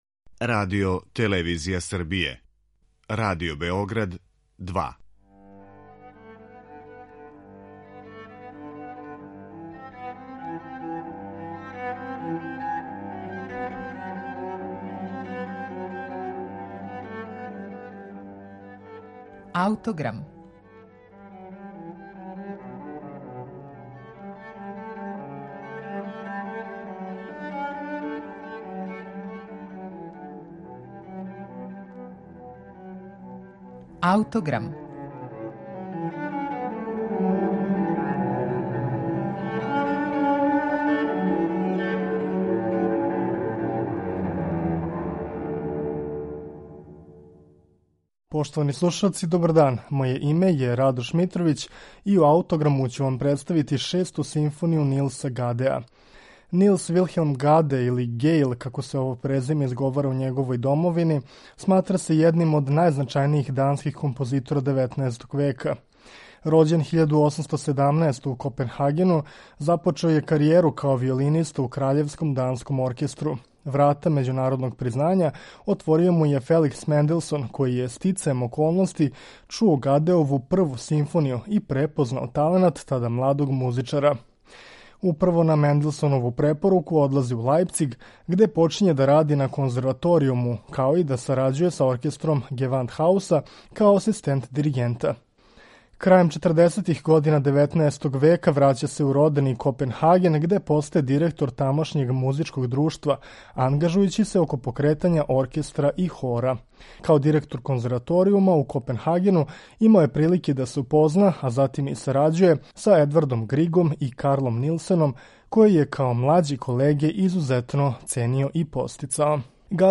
Каснија дела прате савремена европска кретања, црпећи инспирацију из Менделсоновог композиционог приступа.
Шесту симфонију Нилса Гадеа слушаћете у извођењу Симфонијског оркестра Данског националног радија, под управом Кристофера Хогвуда.